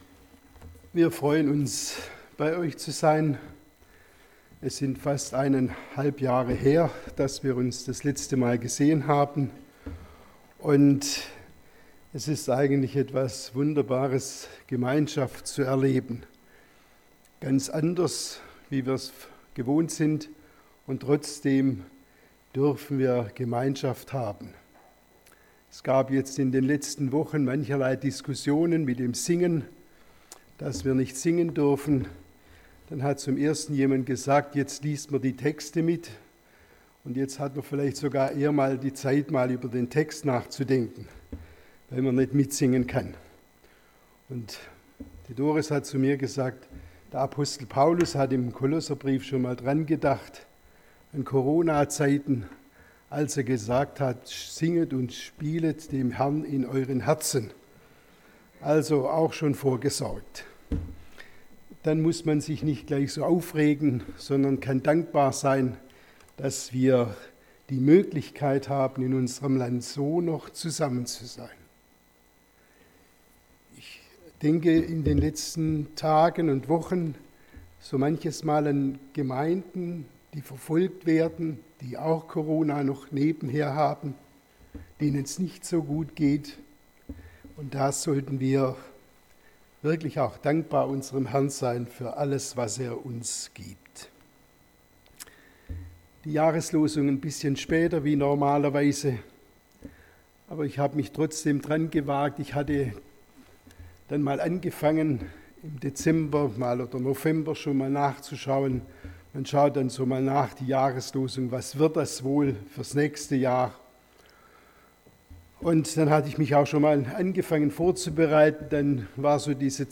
Predigt zur Jahreslosung 2021